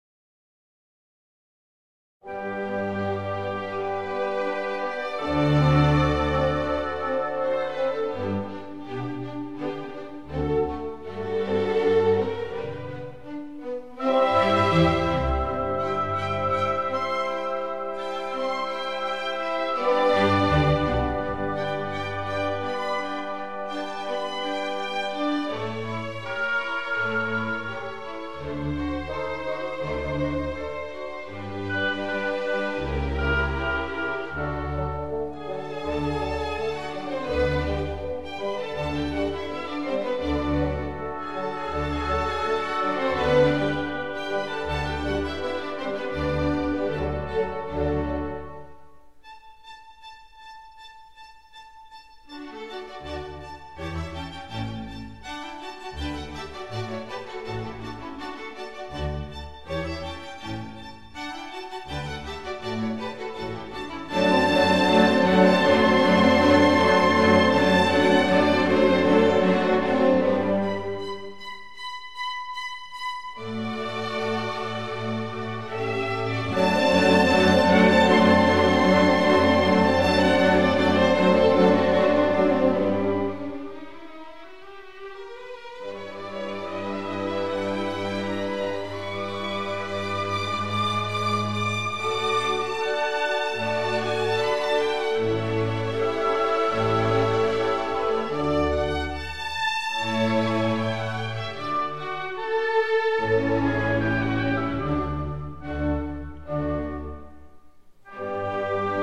Violin: Mozart: Symphony No. 35, Mvt. II (mm. 1-35) – Orchestra Excerpts